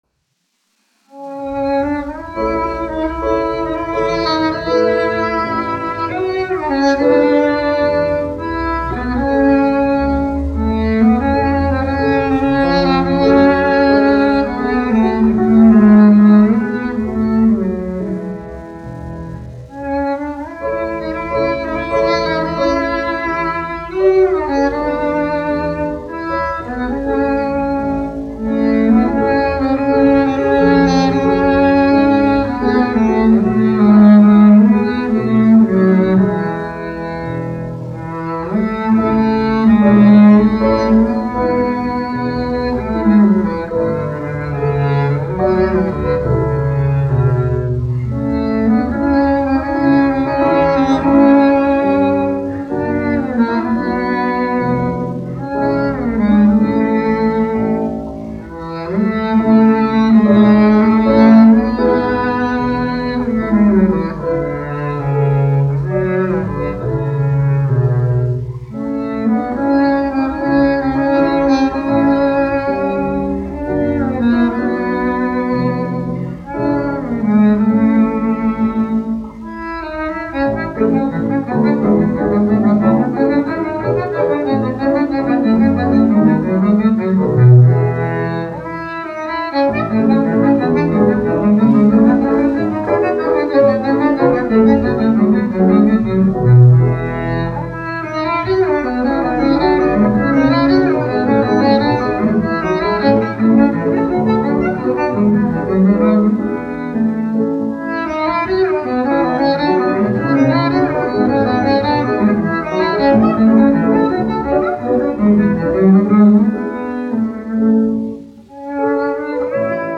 1 skpl. : analogs, 78 apgr/min, mono ; 25 cm
Kontrabasa un klavieru mūzika, aranžējumi